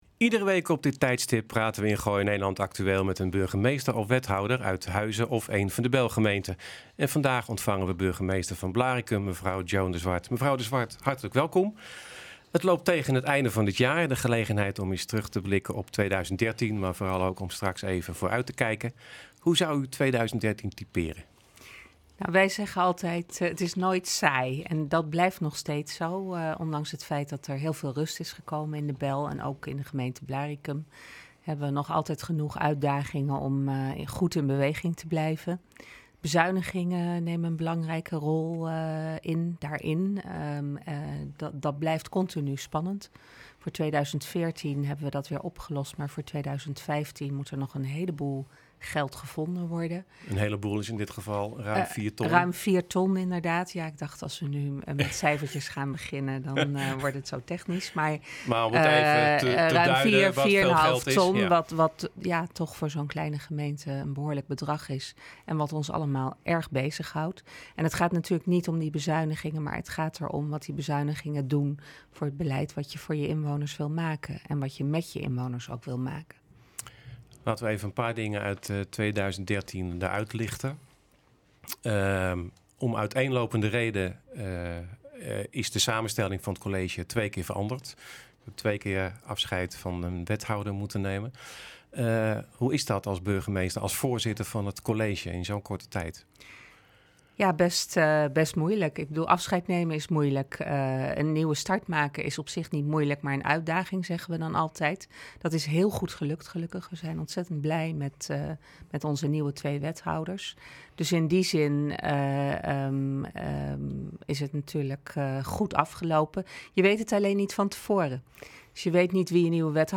Met burgemeester Joan de Zwart van Blaricum blikken wij terug op 2013 en kijken wij vooruit op de jaarwisseling en 2014.